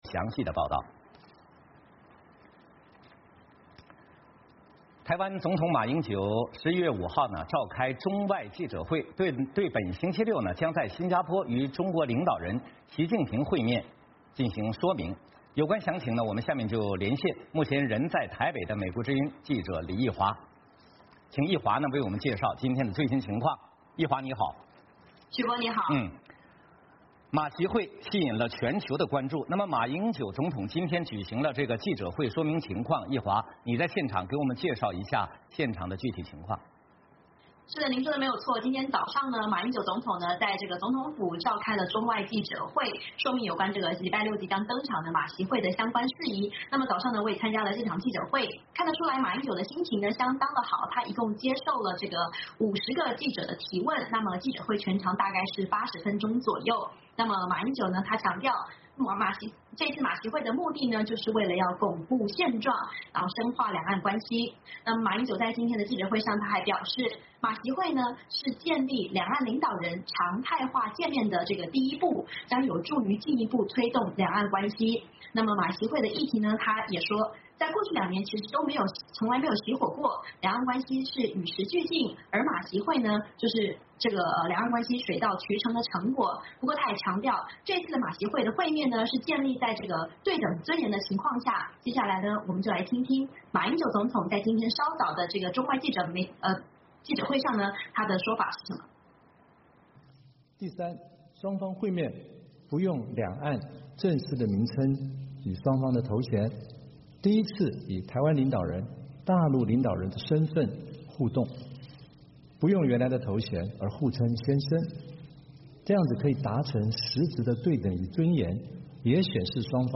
VOA连线：马英九就马习会举行记者会